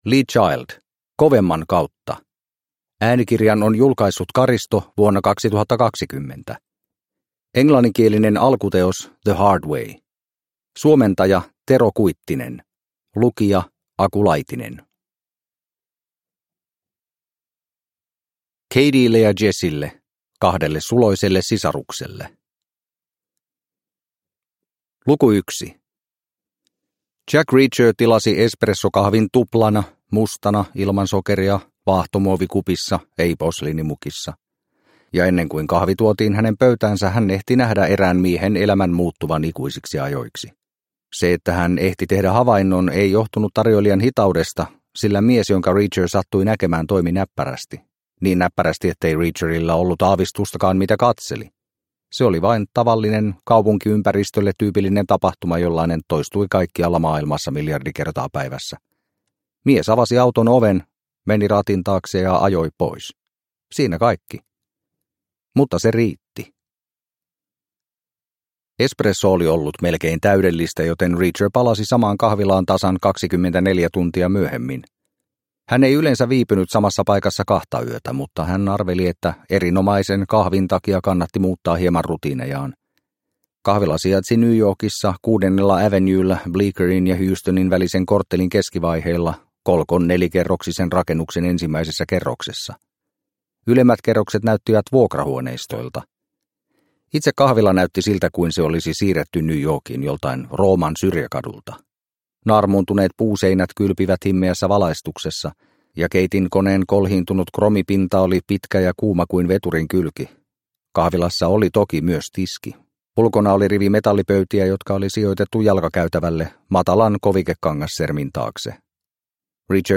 Kovemman kautta – Ljudbok – Laddas ner